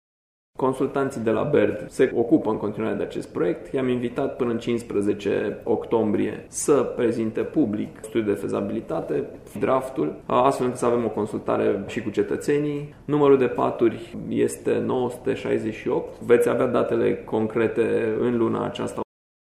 Unitatea medicală va avea peste 900 de paturi. Cel puțin, așa a dat asigurări primarul municipului Brașov, Allen Coliban: